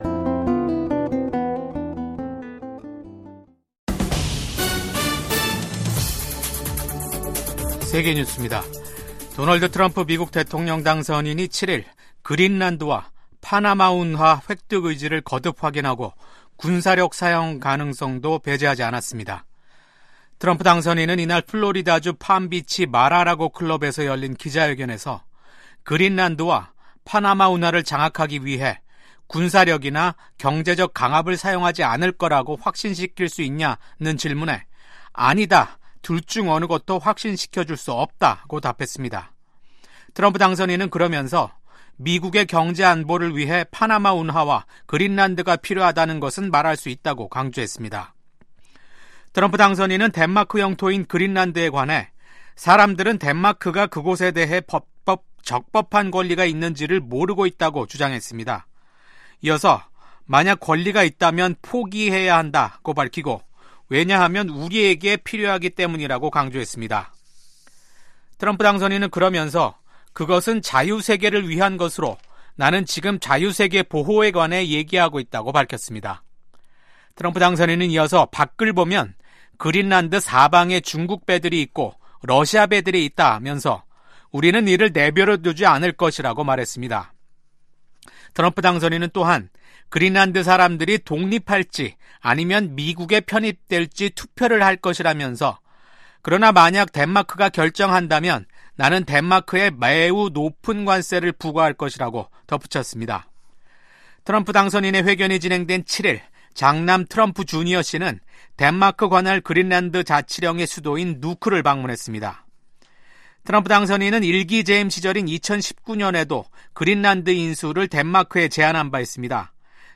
VOA 한국어 아침 뉴스 프로그램 '워싱턴 뉴스 광장'입니다. 토니 블링컨 국무장관이 미한일 3국 공조가 인도태평양 지역의 안정 유지에 핵심적인 역할을 한다고 평가했습니다. 국제사회가 북한의 신형 극초음속 중거리 탄도미사일 발사를 유엔 안보리 결의 위반이라며 강력히 비판하고 나선 가운데, 미국의 미사일 전문가는 북한의 이번 미사일 시험 발사가 실패했을 가능성이 높다고 진단했습니다.